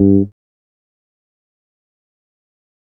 G THUMB.wav